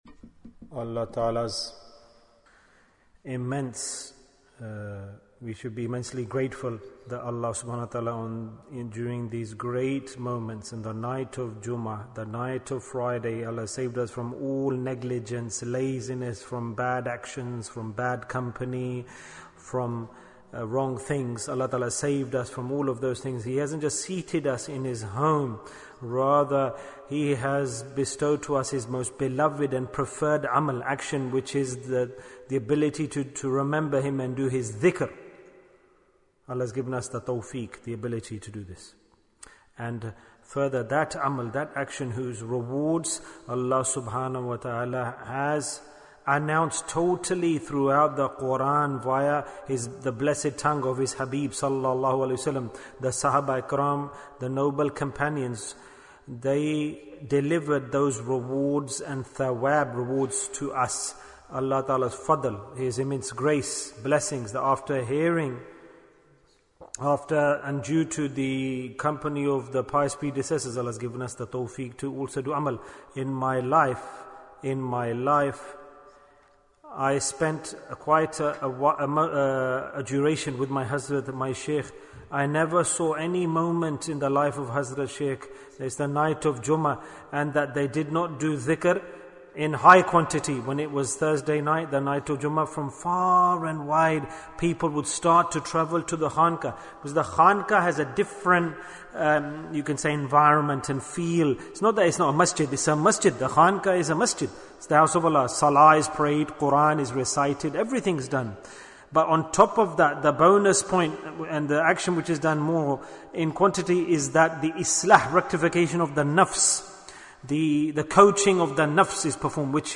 Easy Way Bayan, 58 minutes14th January, 2021